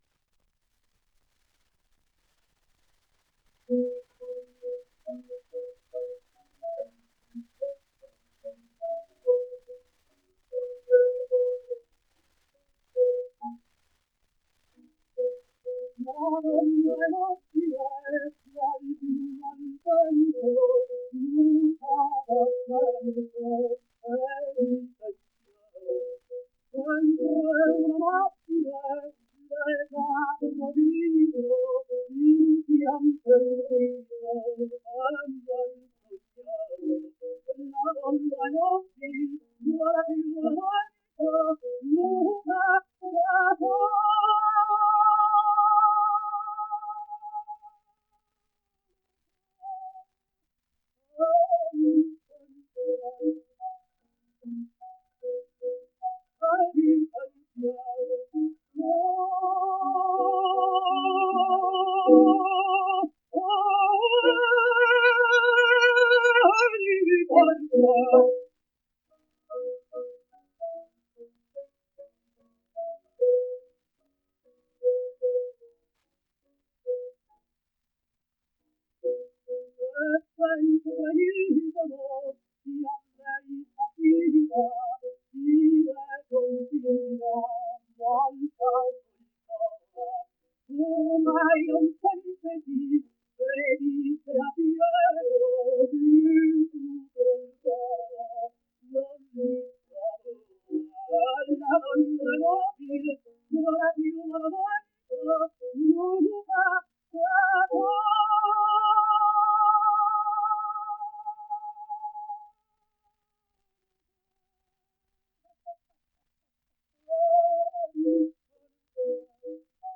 Canzone del Duca : La donna e movile (sonido remasterizado)